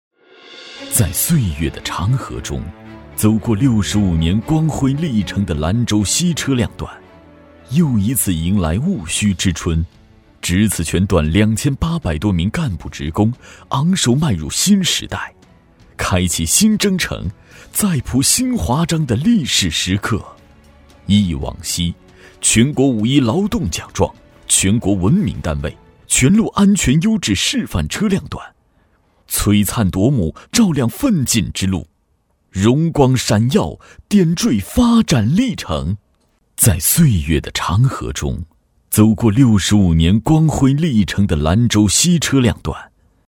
成熟稳重 招商宣传片
大气激情，沉稳厚重男音，擅长政府汇报，宣传片解说，人物讲述等题材。